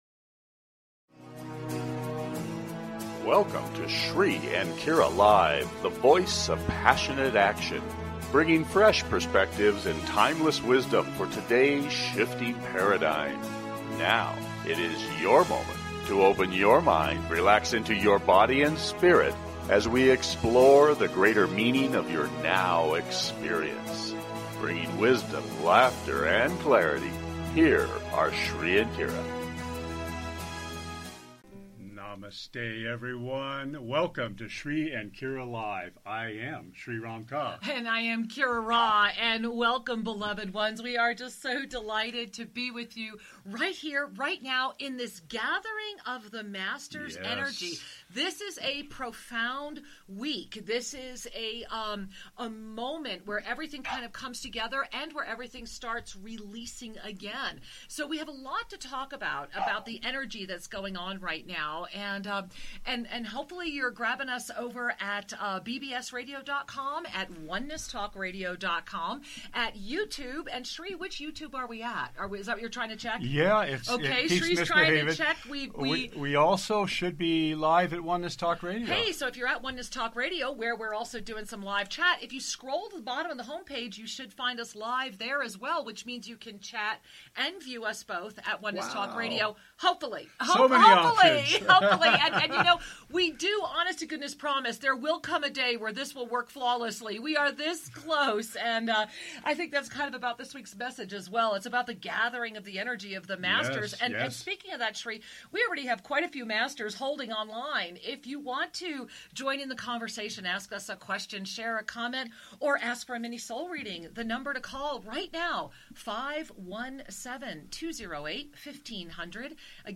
Talk Show Episode, Audio Podcast
Talk Show
Each week they give spiritual guidance and information on a specific topic at hand and open the phone lines to take your calls and offer mini-soul readings.